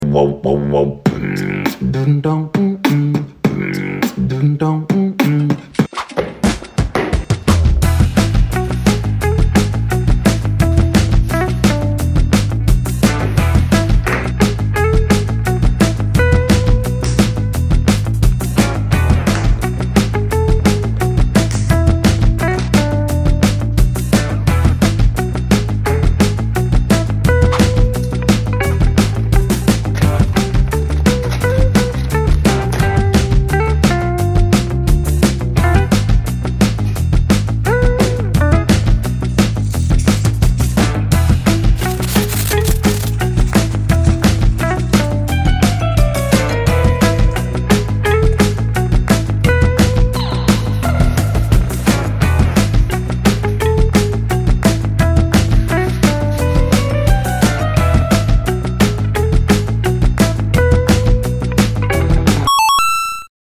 Homemade projector sound effects free download